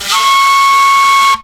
FLUTELIN11.wav